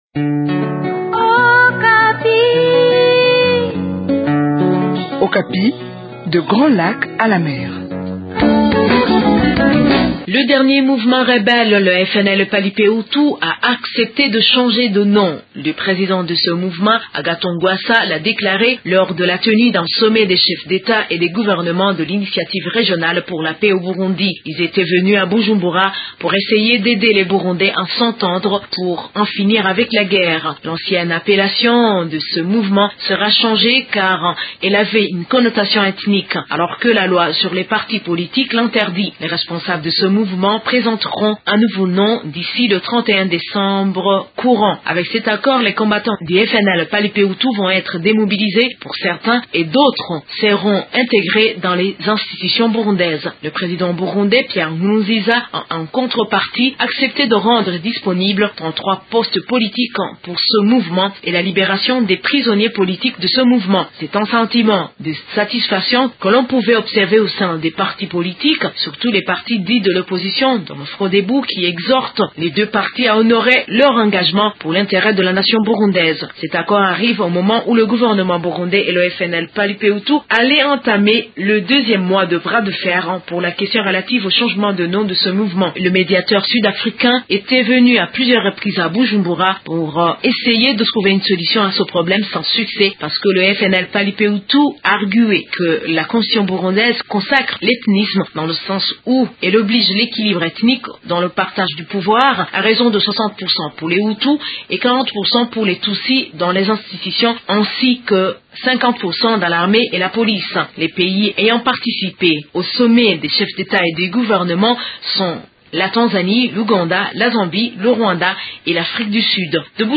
De Bujumbura, une correspondance